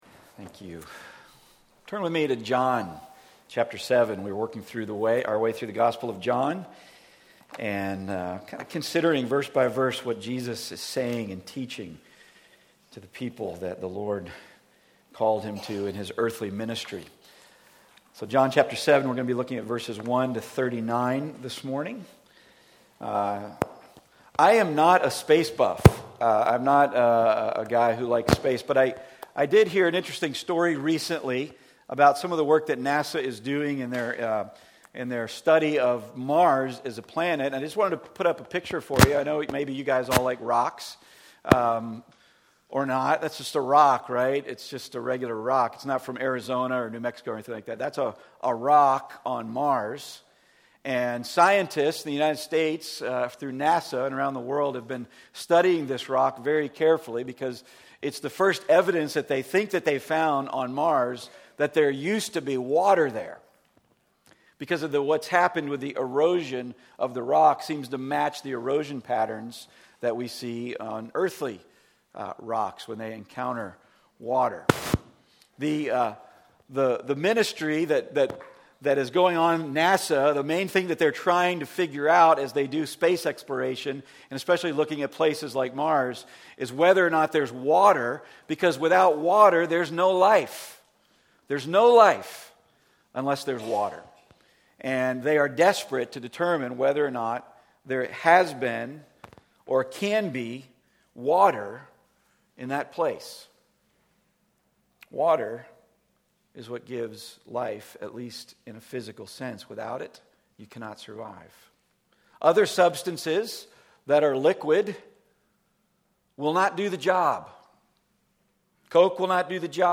Passage: John 7:1-39 Service Type: Weekly Sunday